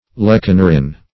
lecanorin - definition of lecanorin - synonyms, pronunciation, spelling from Free Dictionary
Lecanorin \Lec`a*no"rin\, n. (Chem.)